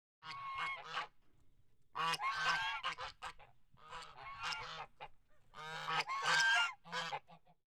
Index of /90_sSampleCDs/E-MU Producer Series Vol. 3 – Hollywood Sound Effects/Human & Animal/Geese
GEESE 2-R.wav